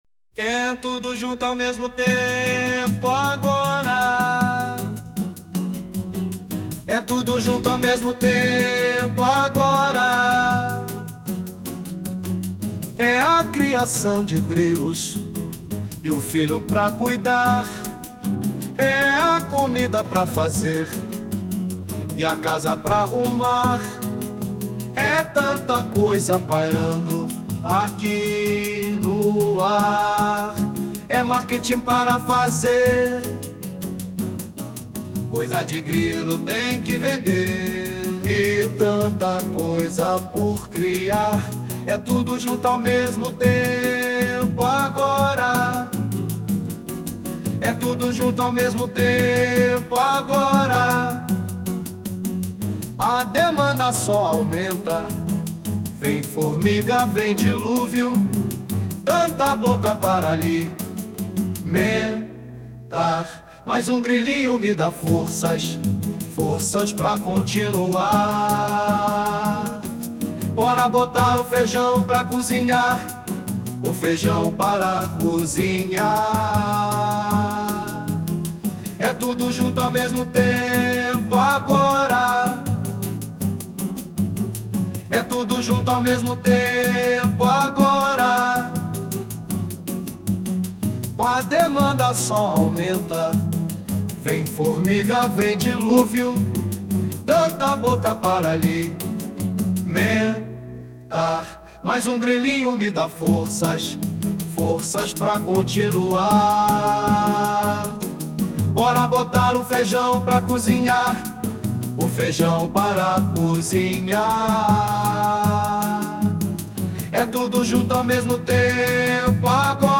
Desculpem-me se algo estiver fora do lugar mas estou cuidando dos grilos enquanto gravo e edito as video aulas, crio este web-site, canal no whatasapp, instagram, facebook, loja virtual, identidade visual, pagar propaganda, fazer caneca, cartão de visitas e musicas (pra interpretação das musicas ao menos a IA salvou!)